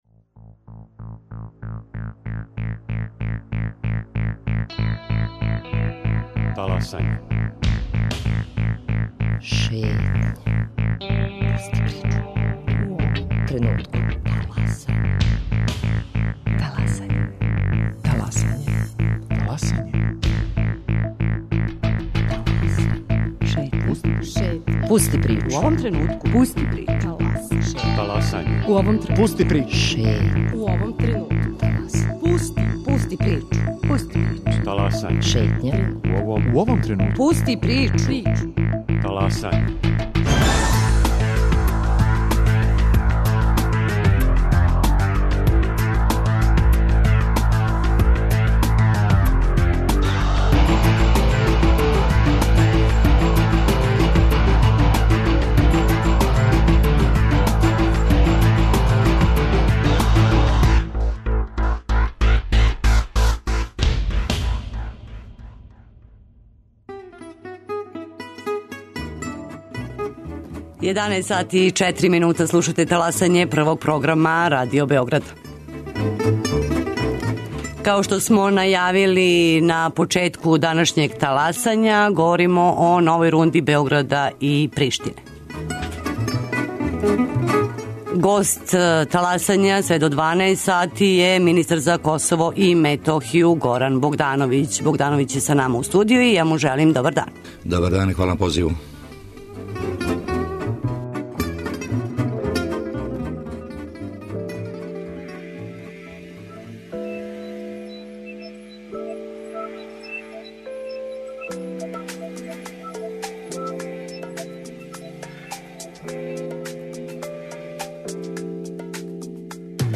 Гост Таласања Горан Богдановић, министар за Косово и Метохију, који ће говорити и о последицама референдума на северу Косова и Метохије, примени досадашњих споразума са Приштином и о покушају косовских власти да спрече кандидатуру Србије .